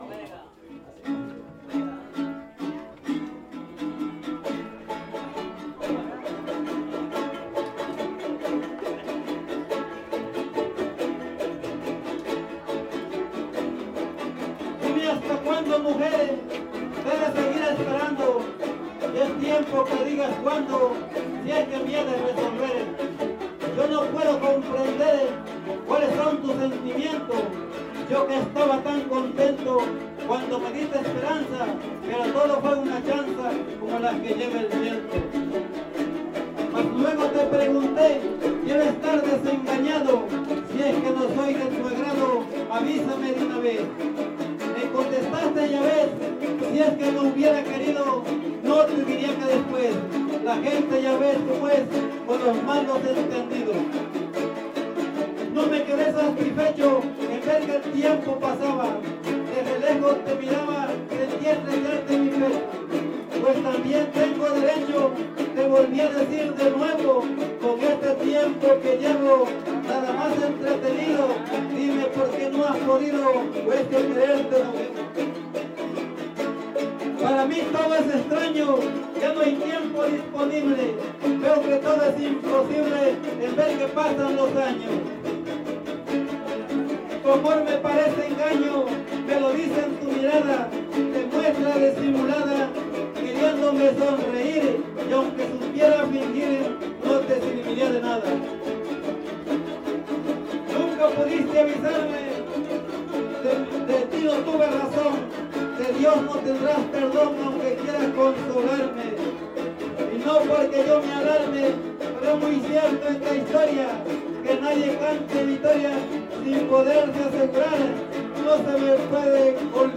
Fiesta Patronal de San Antonio de Padua